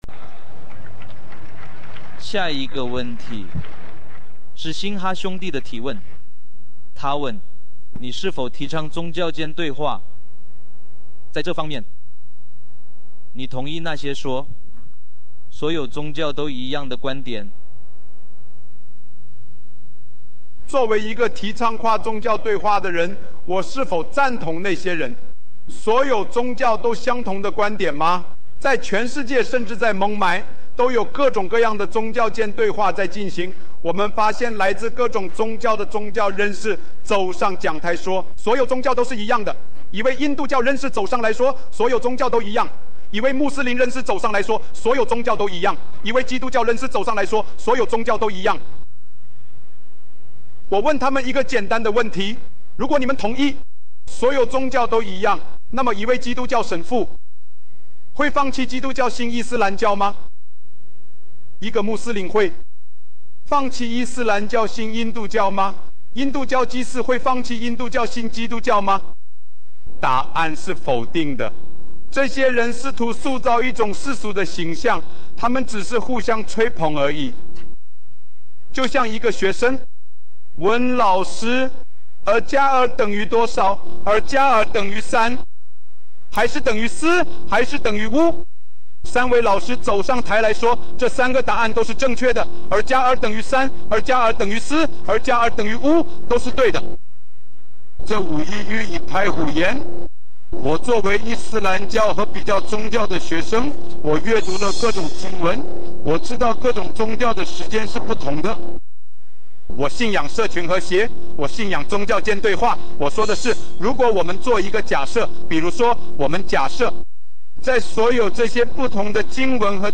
属性: 在这段视频中，扎基尔·奈克博士（Dr. Zakir Naik）解释了为什么声称“所有宗教都一样”是不公平的。